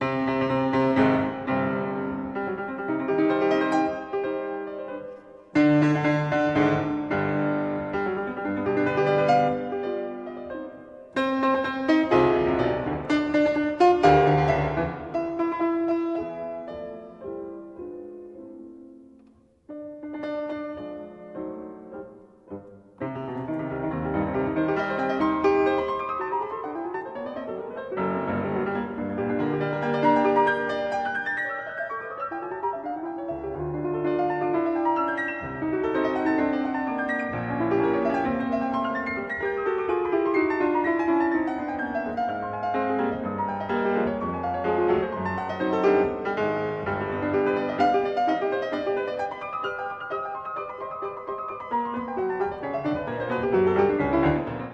I. Allegro con fuoco
piano